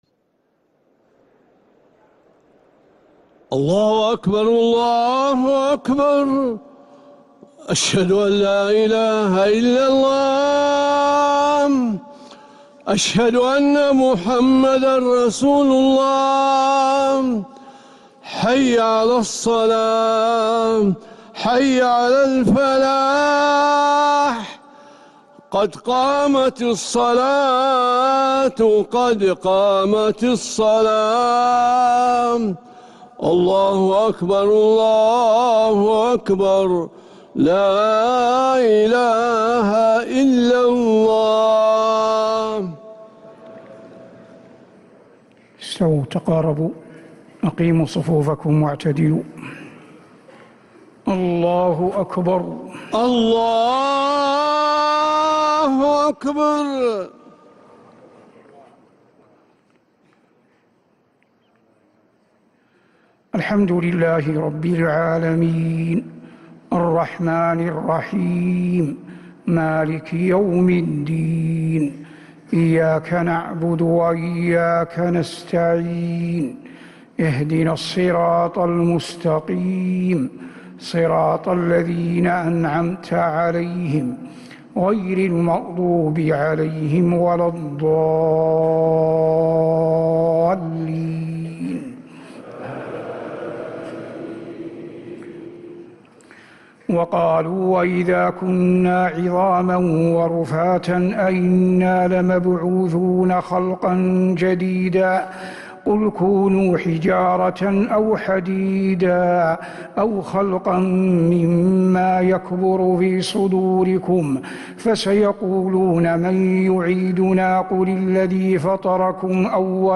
Madeenah Isha - 27th March 2026